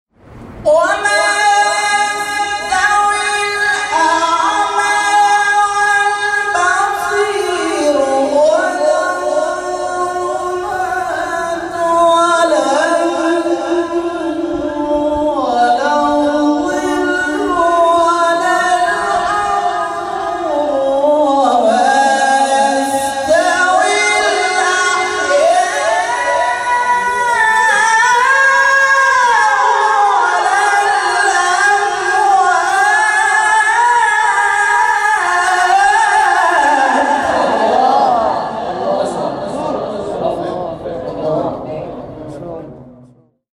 این فرازها به ترتیب در مقام‌های؛ نهاوند، عزام، بیات، حجاز و رست اجرا شده است.